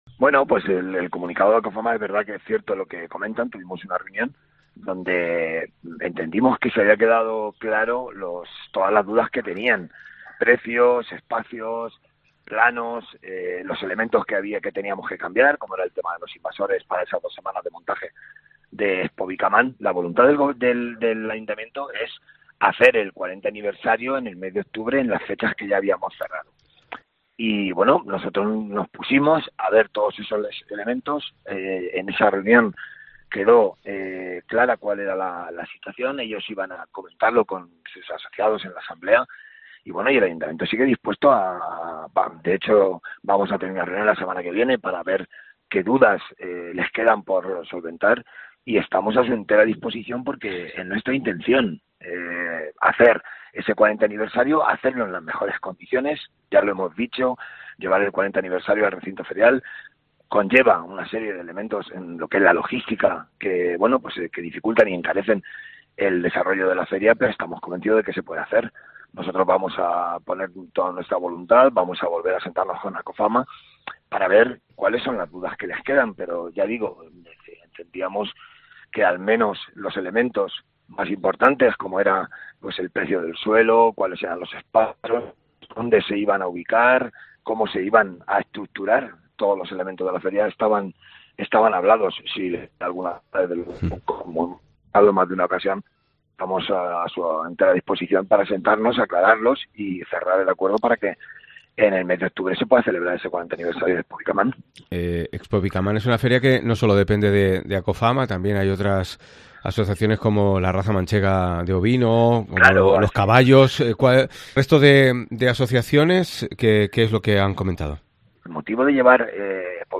Modesto Belinchón, en declaraciones a COPE Albacete aseguraba que en la reunión del pasado 3 de marzo se expuso el diseño de la Feria, fechas, tasas... pero si hay algo que explicar a las asociaciones que participan en la feria, las puertas del ayuntamiento están abiertas.